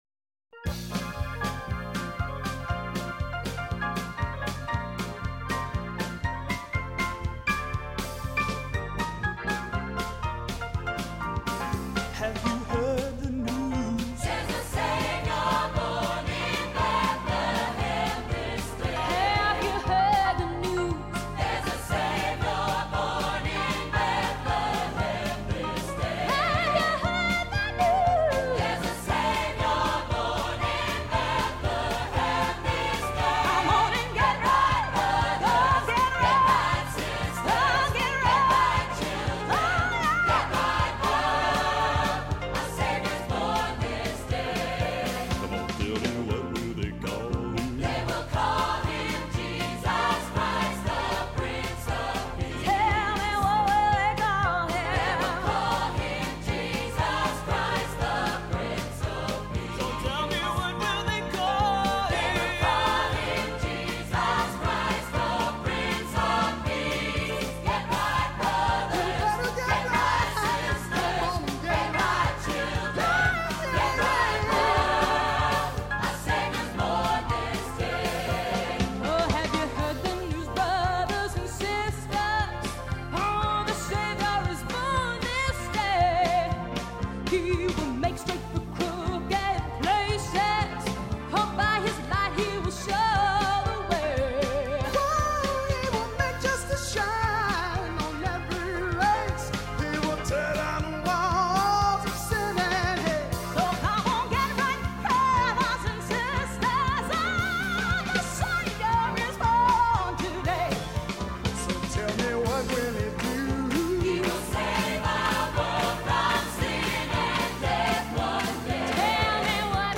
Voicing: SATB, assembly,Soloist or Soloists